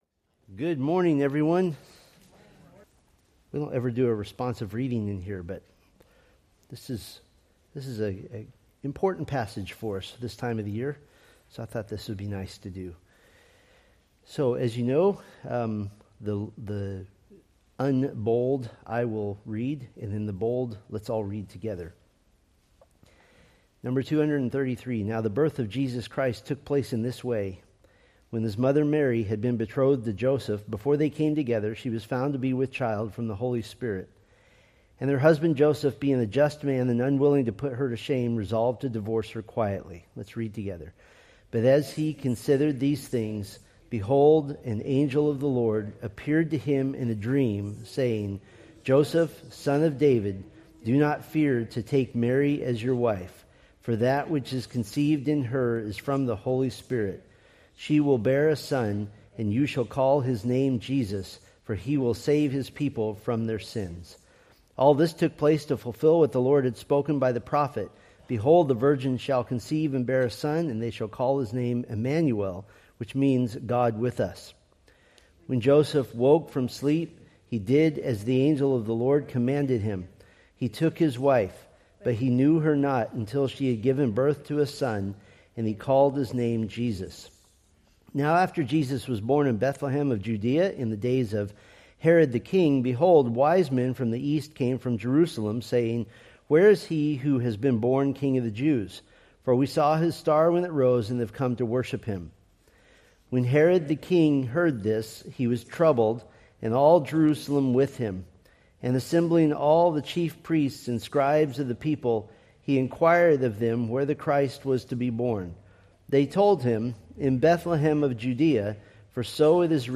Date: Dec 7, 2025 Series: Psalms Grouping: Sunday School (Adult) More: Download MP3